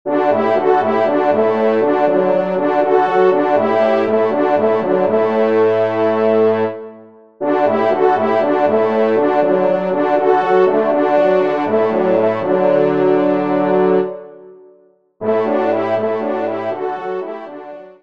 Genre :  Divertissement pour Trompes ou Cors
3ème Trompe